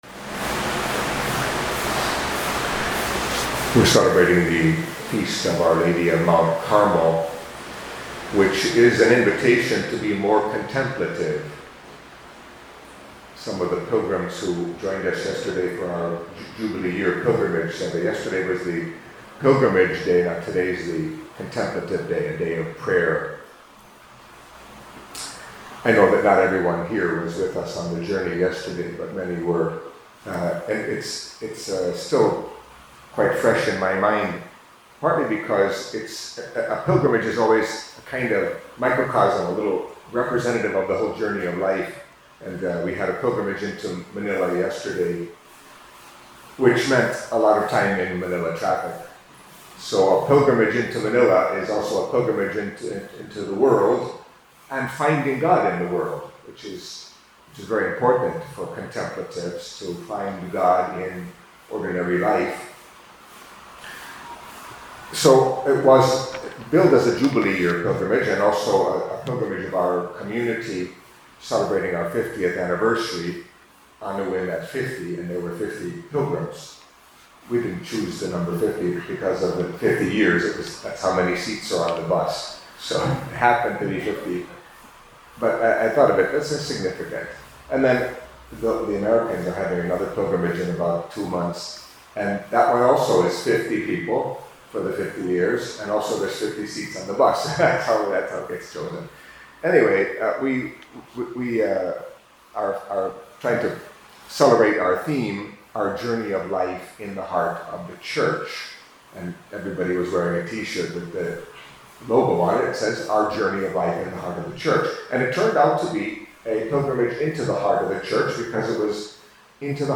Catholic Mass homily for Wednesday of the Fifteenth Week in Ordinary Time